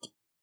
BottleFoley3.wav